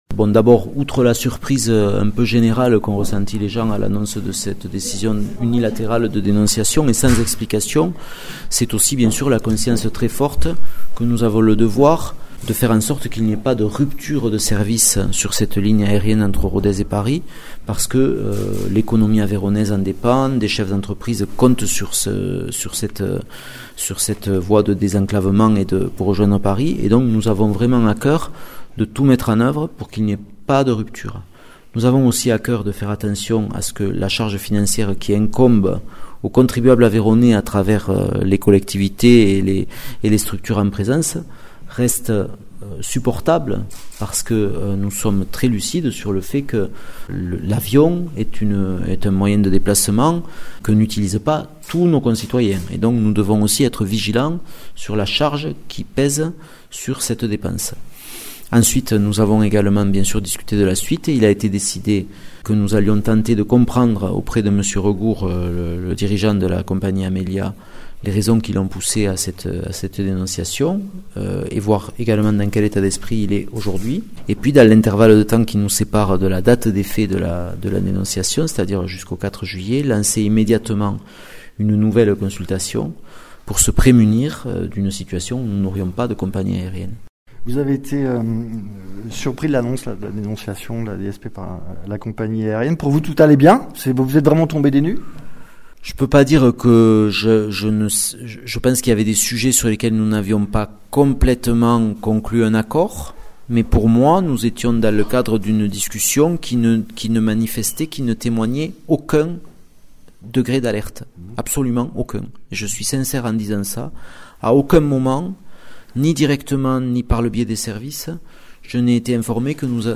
Interviews
Invité(s) : Arnaud Viala, Président du conseil départemental de l’Aveyron, Président du syndicat mixte de l’aéroport de Rodez